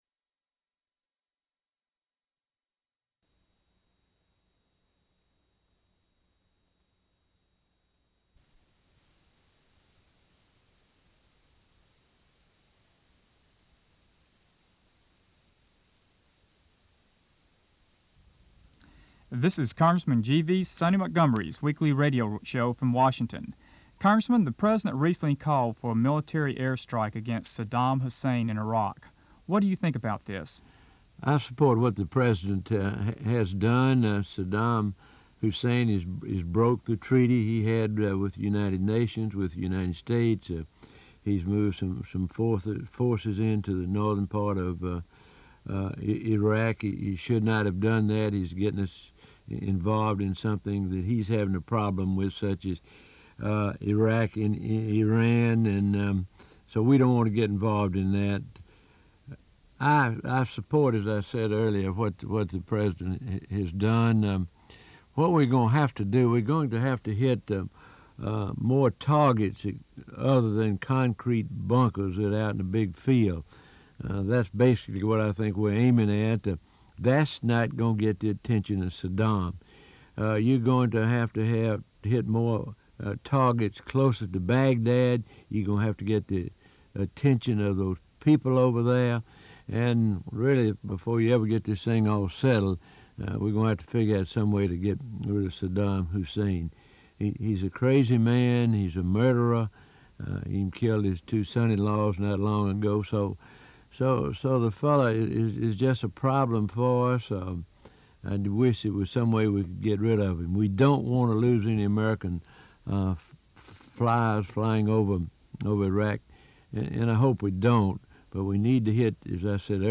Weekly Radio Addresses